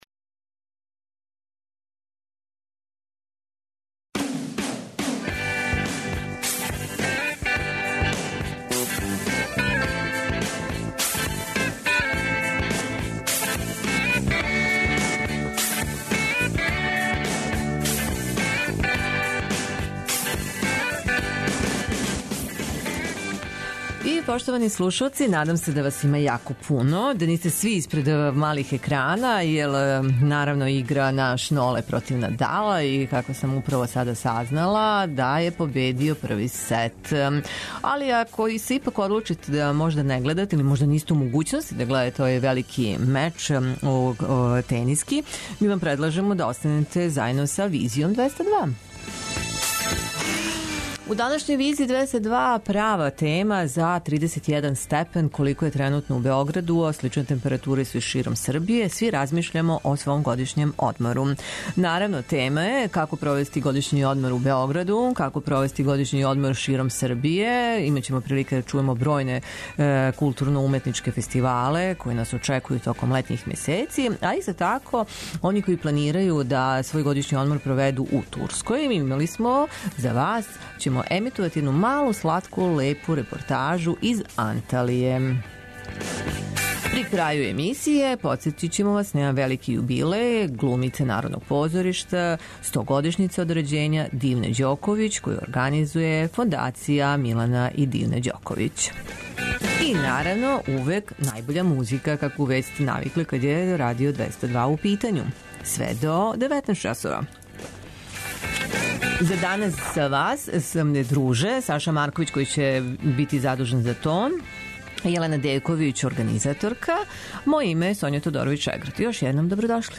Чућете и репортажу коју смо забележили током боравка у Анталији у Турској. Сазнаћете шта све овај град, пети по величини у Турској нуди туристима из Србије.